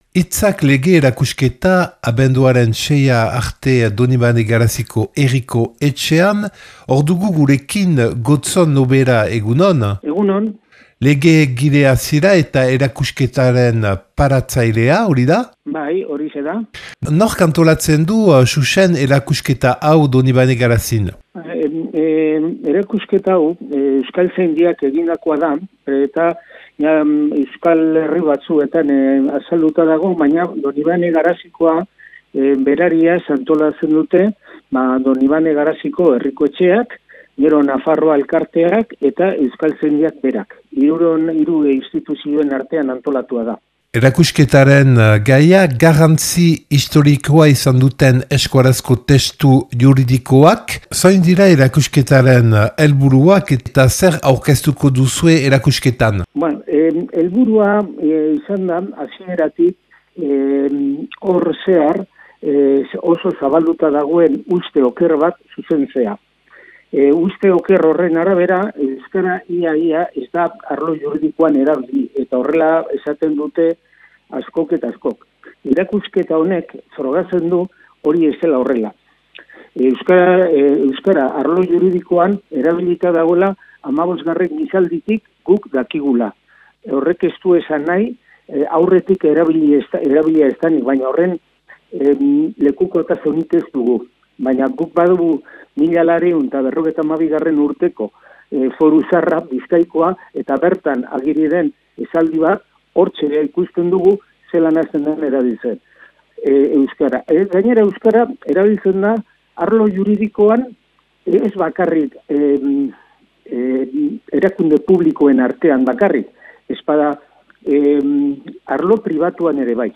07H40 | Elkarrizketak eta erreportaiak